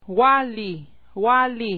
Tabla I: Alfabeto Oficial sonorizado
Pseudovocales w